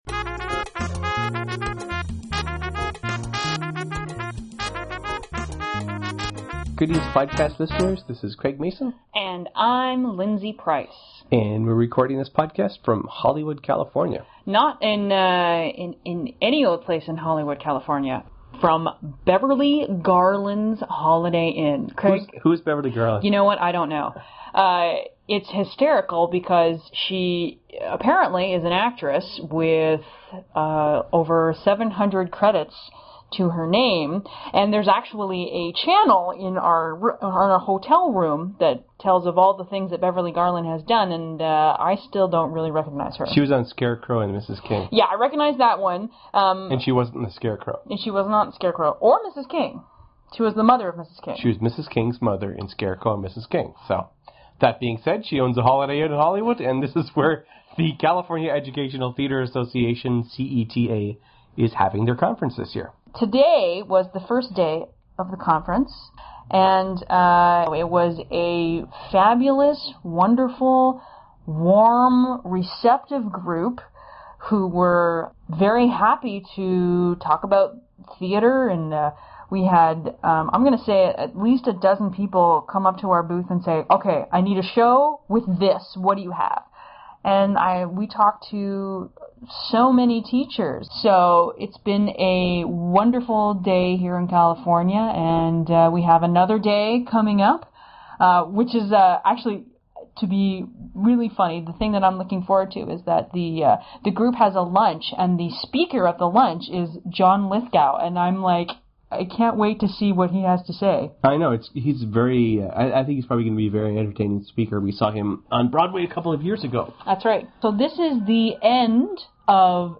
Recorded live in Hollywood, California!